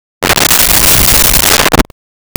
Creature Growl 03
Creature Growl 03.wav